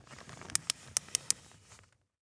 Plastic Bag Close Sound
household
Plastic Bag Close